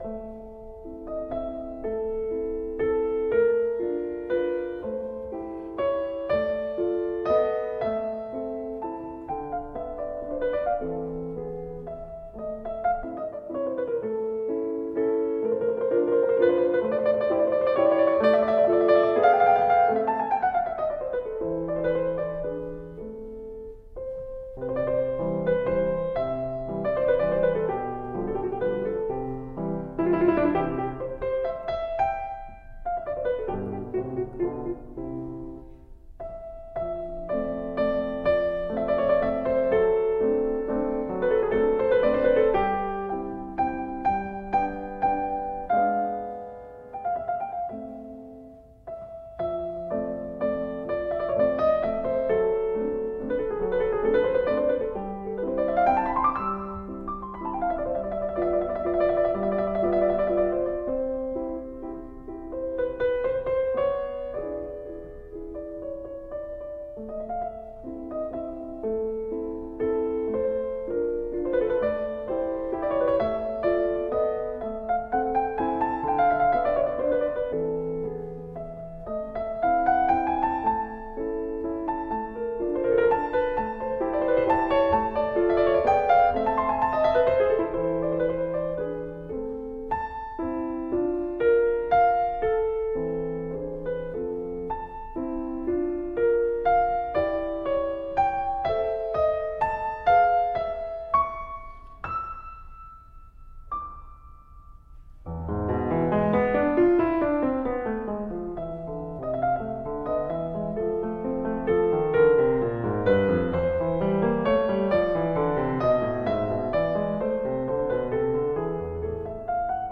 DESCRIPTION OF THE PIECE: a lovely single-movement piano piece in rondo form.
And that brings us back to the final “A” section, which is essentially the same as the first A, but with a lot more elaboration and an extended “coda” section at the end which brings back some of the sixteenth-note triplets from the “C” part.
Rondo final A